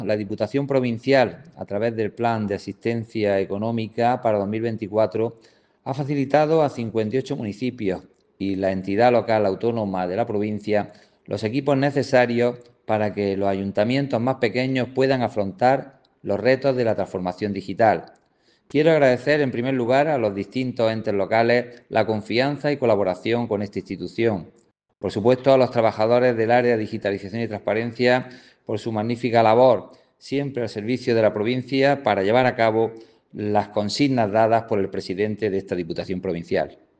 Audio-Diputado-Manuel-Cortes.mp3